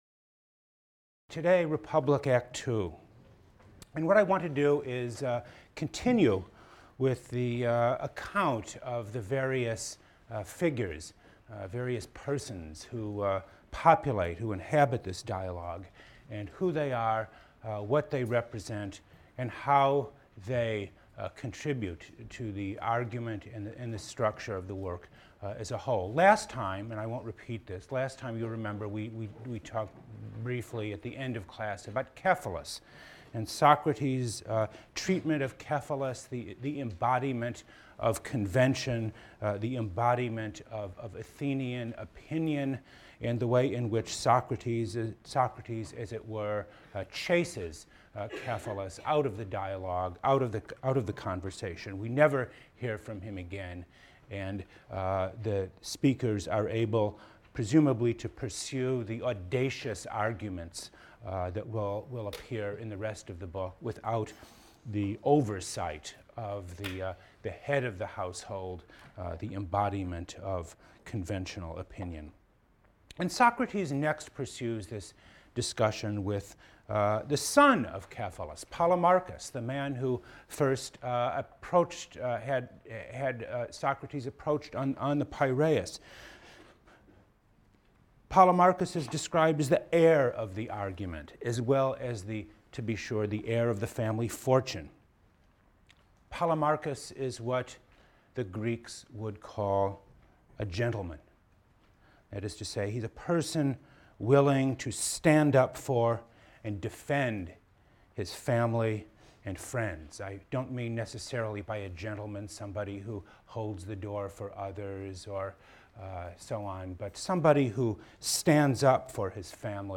PLSC 114 - Lecture 5 - Philosophers and Kings: Plato, Republic, III-IV | Open Yale Courses